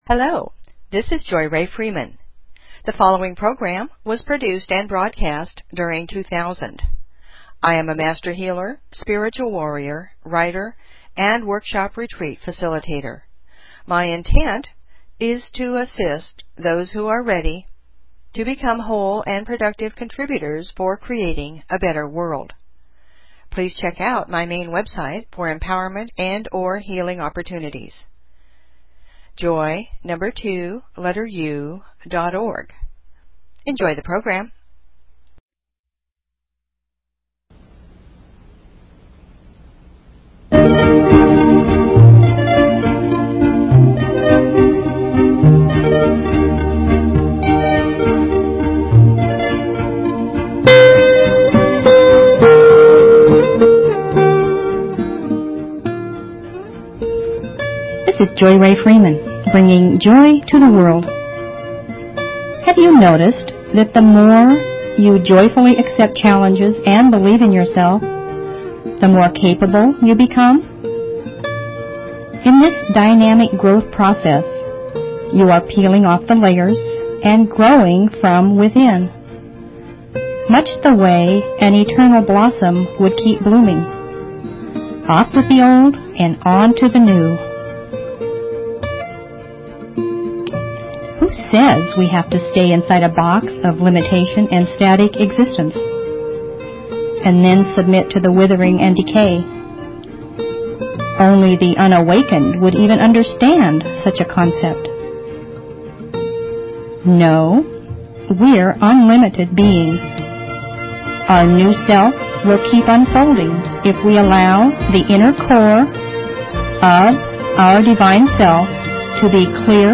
Talk Show Episode, Audio Podcast, Joy_To_The_World and Courtesy of BBS Radio on , show guests , about , categorized as
JOY TO THE WORLD - It's a potpourri of music, INSPIRATION, FOLKSY FILOSOPHY, POETRY, HUMOR, STORY TELLING and introductions to people who are making a difference. It's lively, but not rowdy - it's sometimes serious, but not stuffy - it's a little funny, but not comical - and most of all - it's a passionate, sincere sharing from my heart to yours.